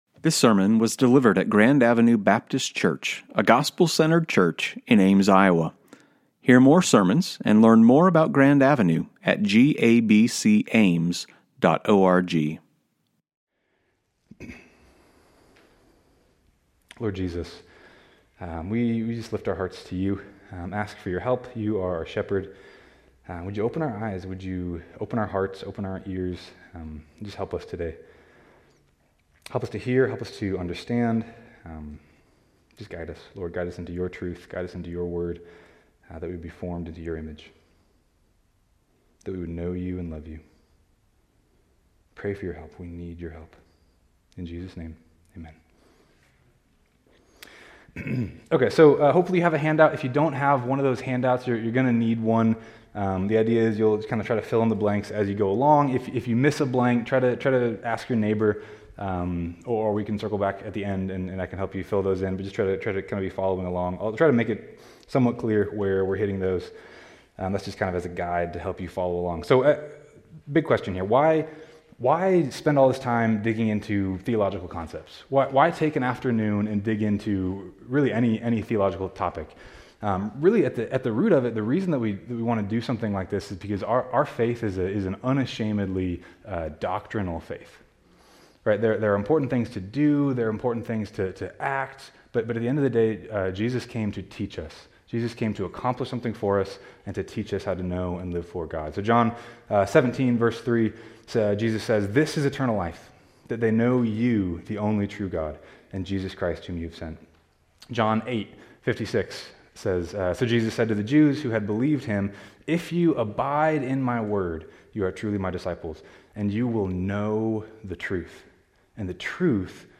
2025 Author GABC Soundbooth Category One Day Seminar Date